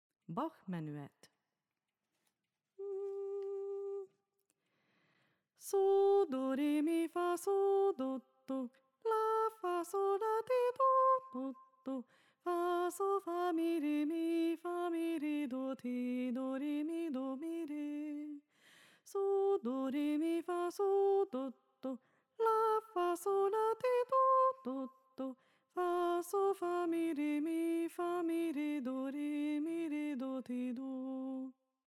Régi barokk táncok és új stílusú magyar népdalok, táncdallamok